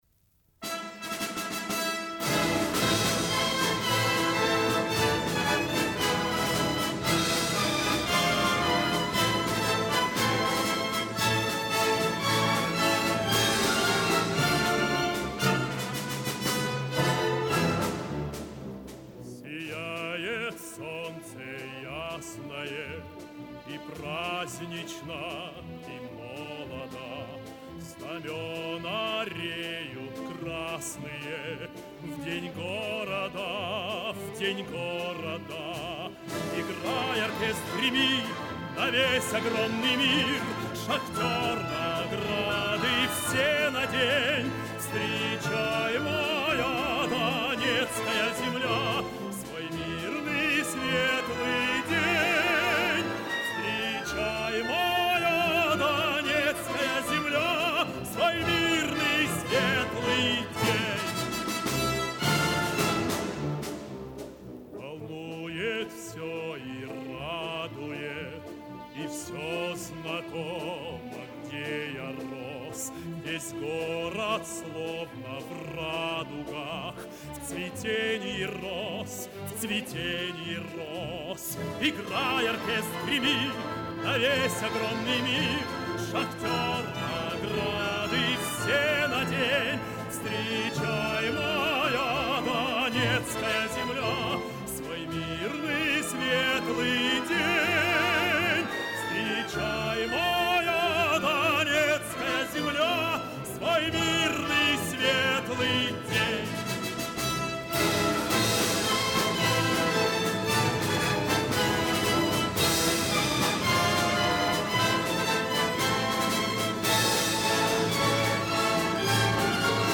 Солисты: